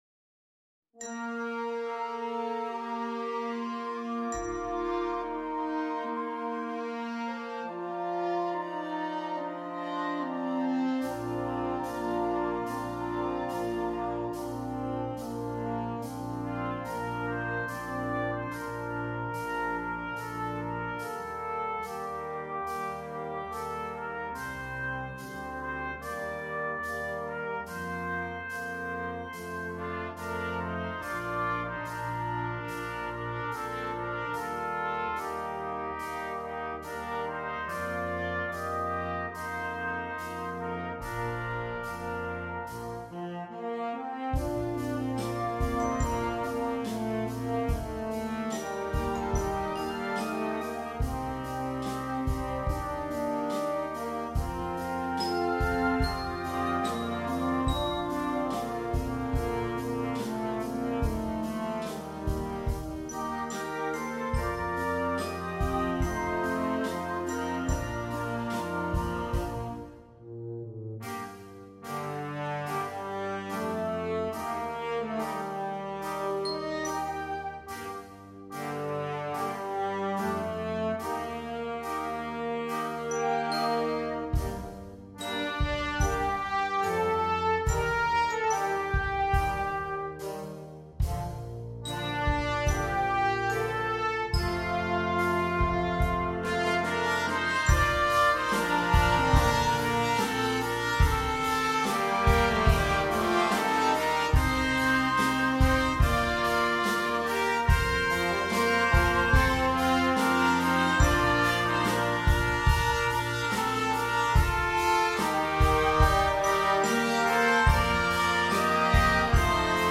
Wundervolle Ballade für Jugendorchester.